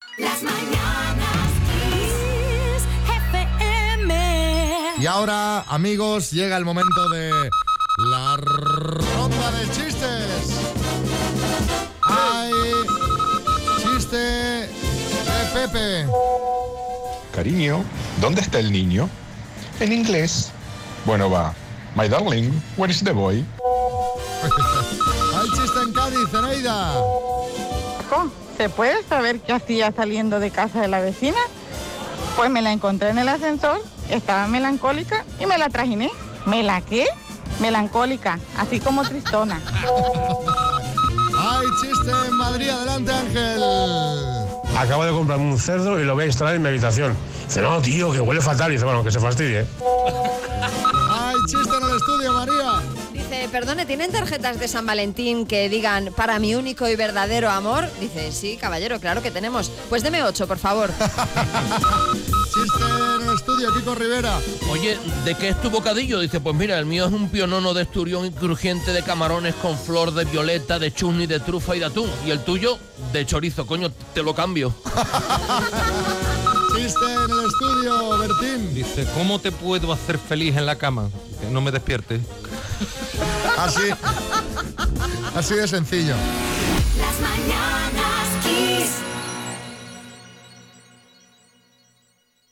Nueva ronda de risas aseguradas
Chistes desde Madrid, Cádiz y en el estudio